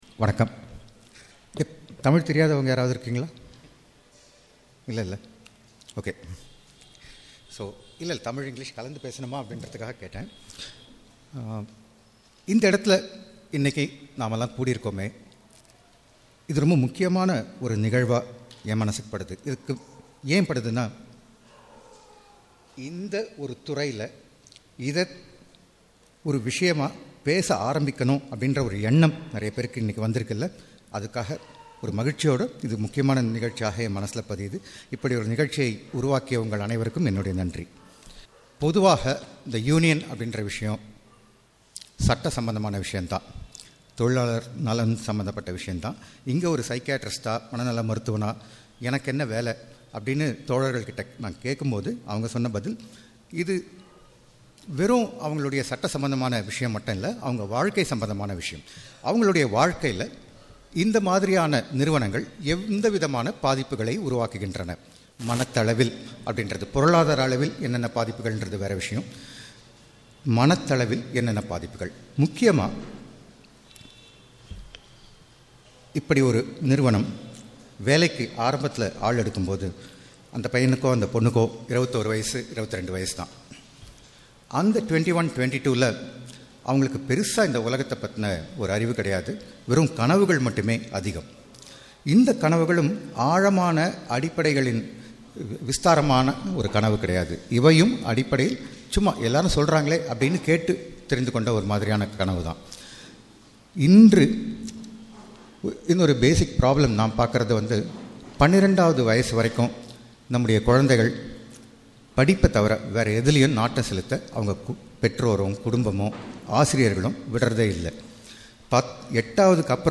ஐ.டி துறை யூனியன் – கலந்துரையாடல் வீடியோக்கள்